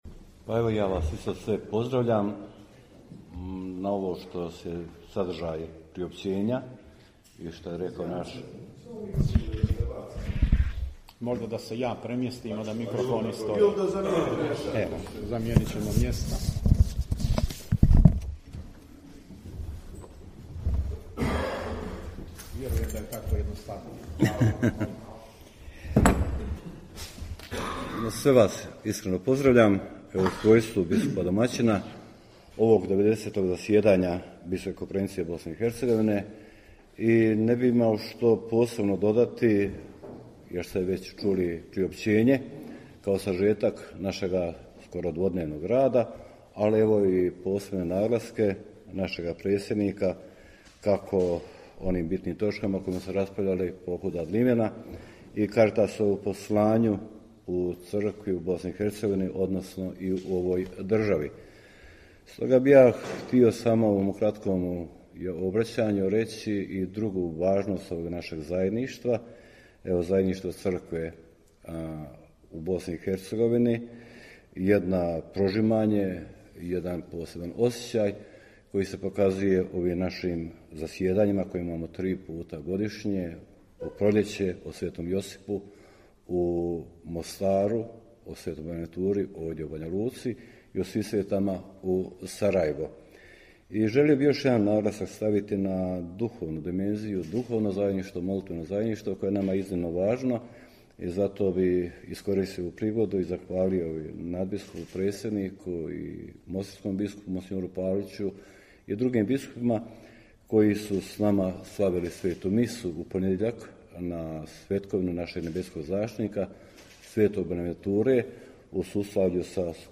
Audio: Nadbiskup Vukšić i biskup Majić na konferenciji za novinstvo govorili o 90. redovitom zasjedanju BK BiH